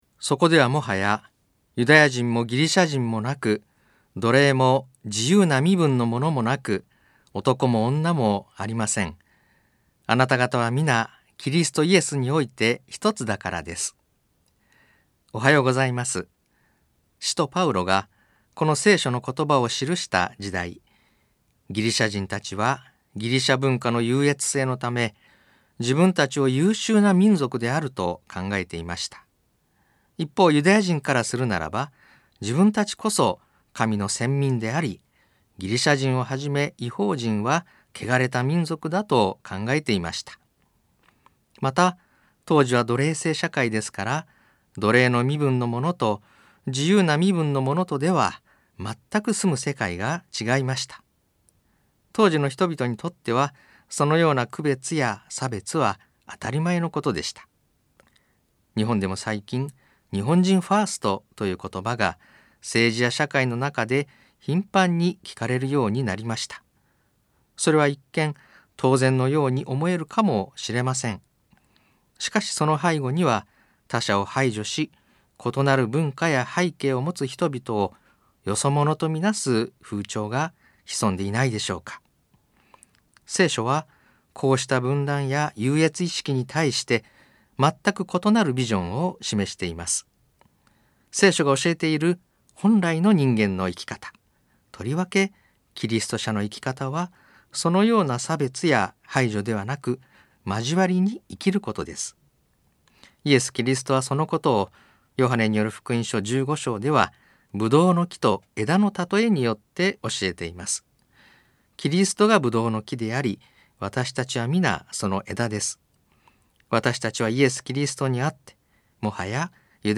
ラジオ番組